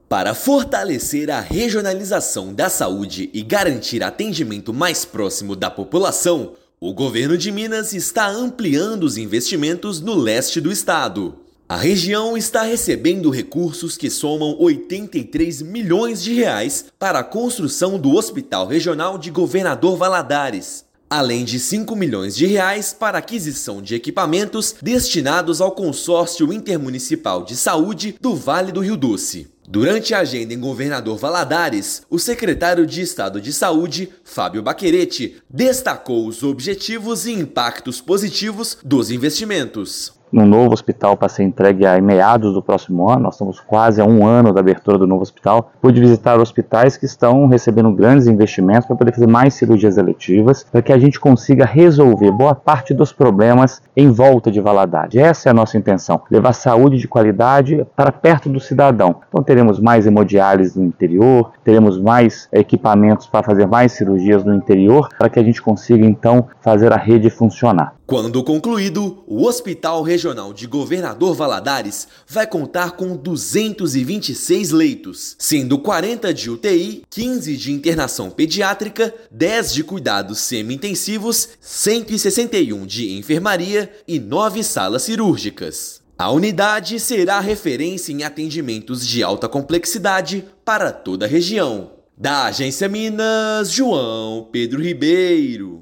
Objetivo é garantir que a população seja atendida em seu município, com encaminhamento para Governador Valadares apenas para casos mais graves. Ouça matéria de rádio.